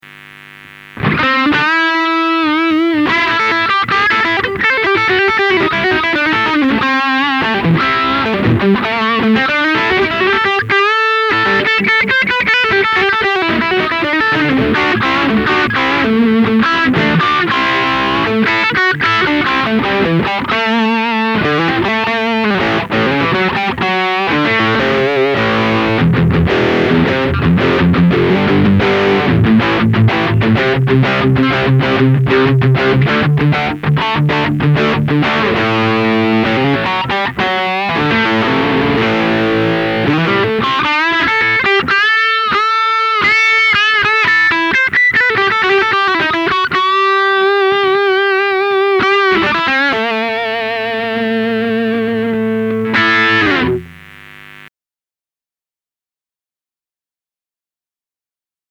Les sons sont limite exploitables, c'est encore moins bon que les amplug, pourtant pas terrible.
Waves iGtr - Lead
Mais on sent que clairement que le son n'est pas haut de gamme.
LP R8 - PRX908 - Igtr - Lead.mp3